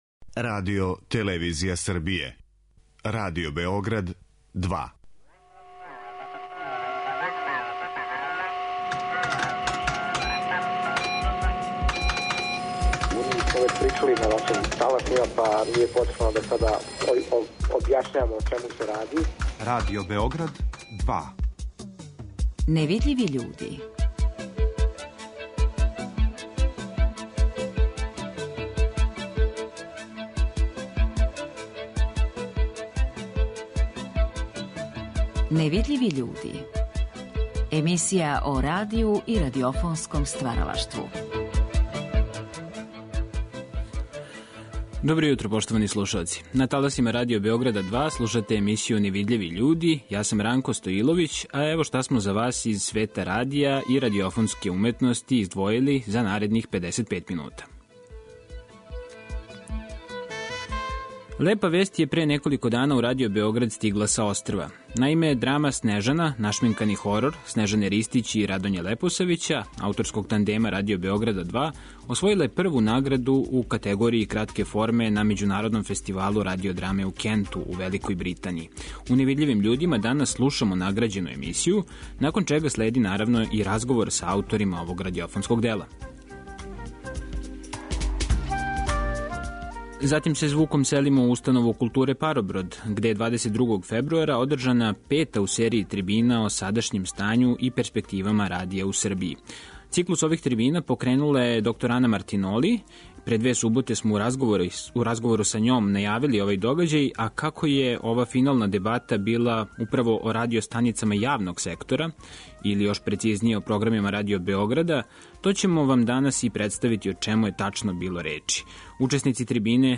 Чућете њихова размишљања и заинтересованост великог броја присутних, у звучној слици коју смо вам припремили са ове трибине.